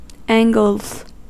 Ääntäminen
Ääntäminen US Haettu sana löytyi näillä lähdekielillä: englanti Käännöksiä ei löytynyt valitulle kohdekielelle. Angles on sanan angle monikko.